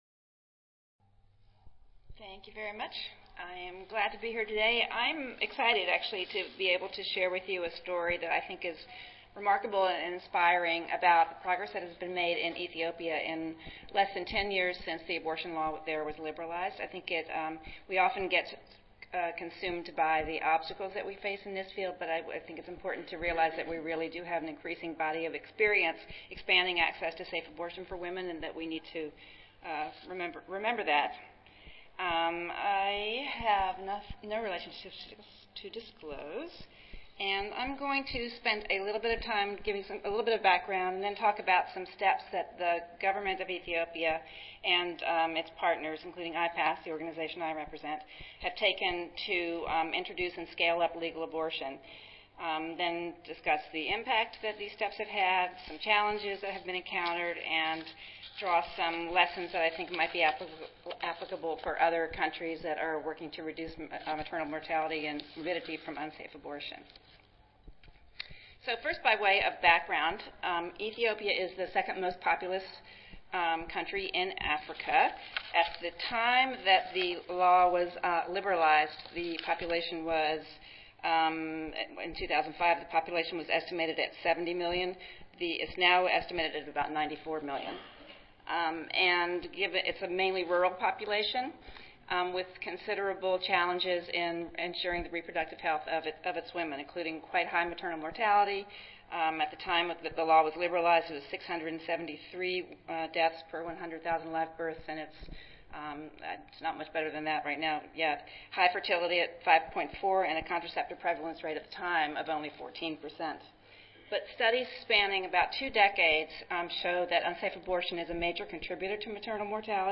142nd APHA Annual Meeting and Exposition (November 15 - November 19, 2014): Introduction and scale-up of legal abortion in Ethiopia: Strong results in under 10 years